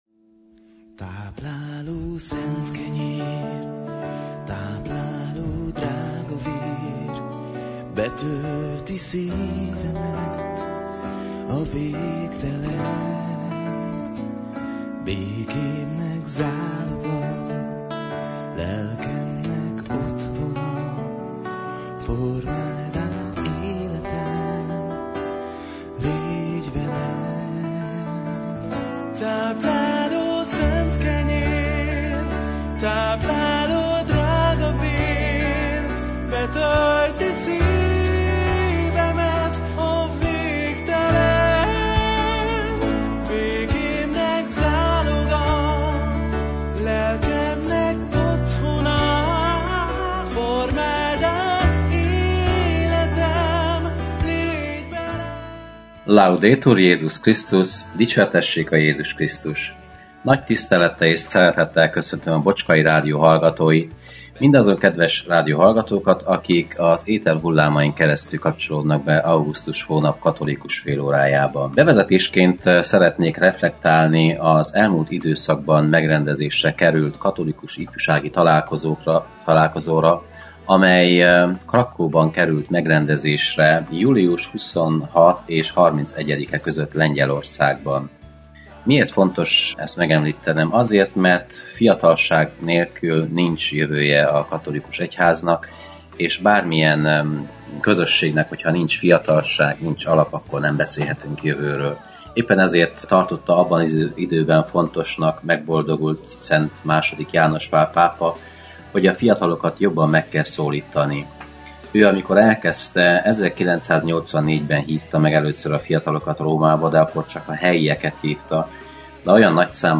Igét hirdet
a clevelandi Szent Imre Katolikus Templomból.